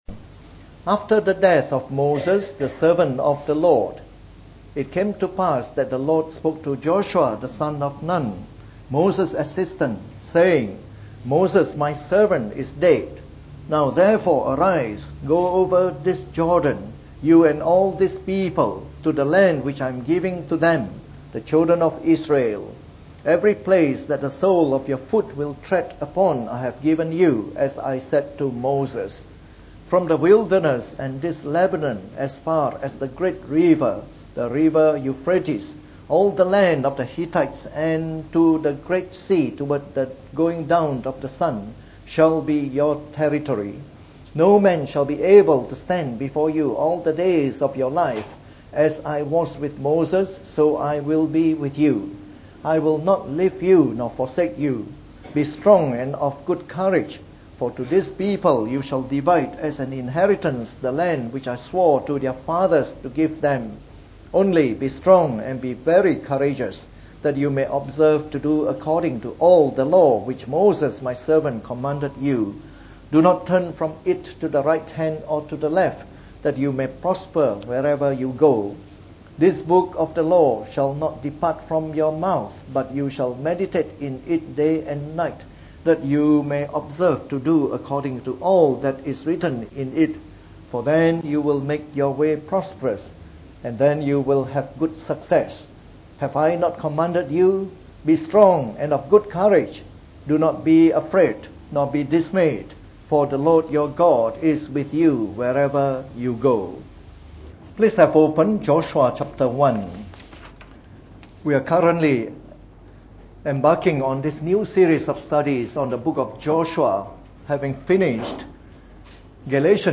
From our new series on the Book of Joshua delivered in the Morning Service.